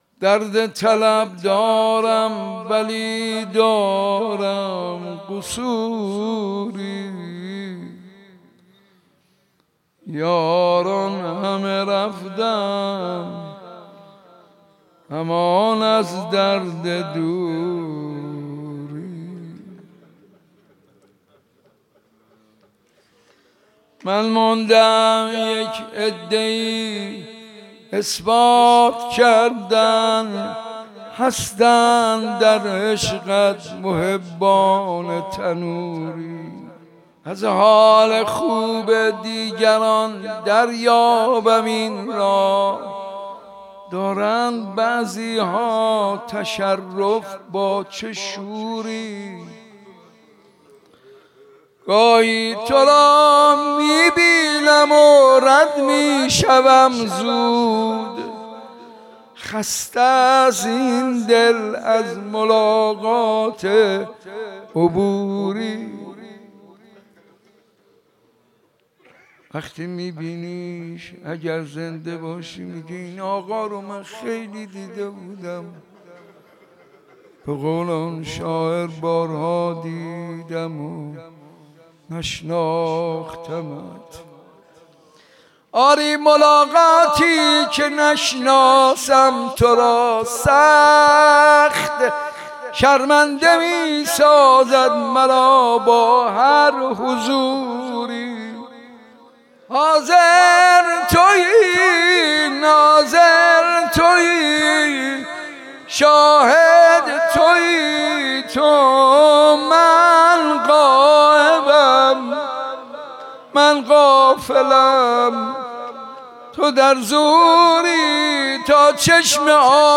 در مجلس حسینیه صنف لباس فروشان
مداحی به سبک مناجات اجرا شده است.